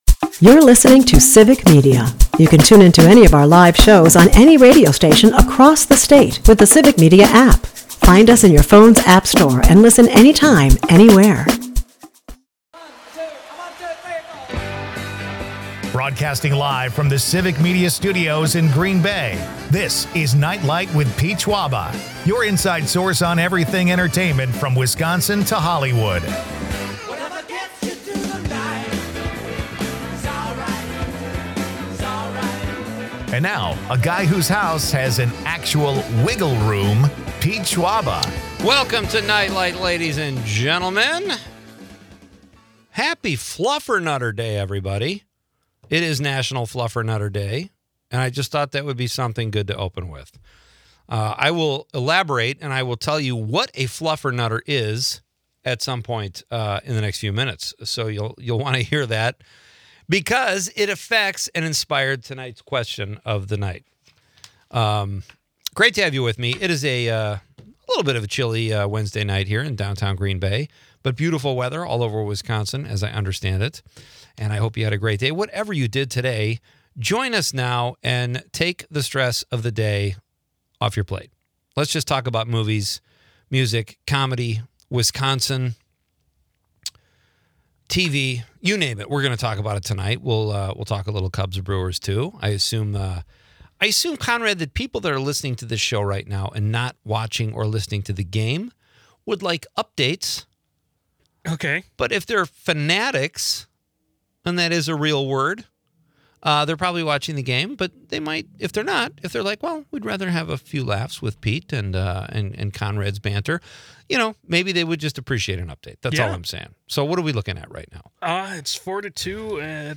Leave the stress of the day behind with entertainment news, comedy and quirky Wisconsin.